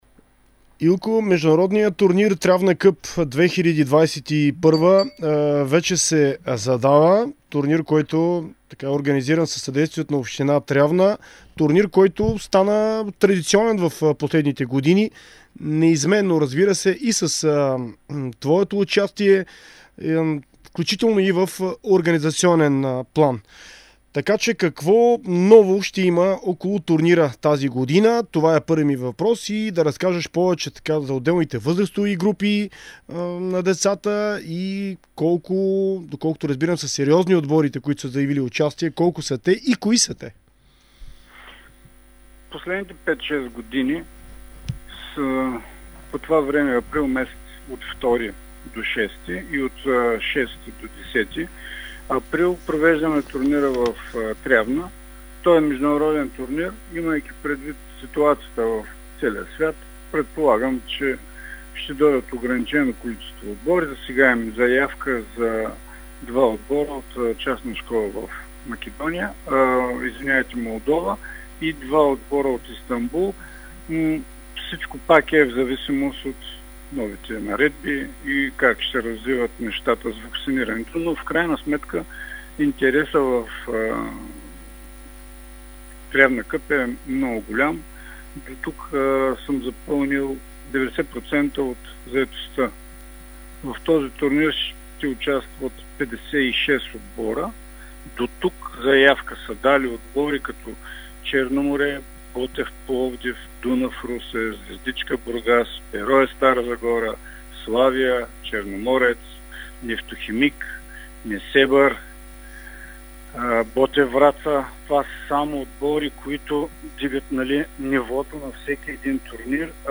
интервю за Дарик радио и dsport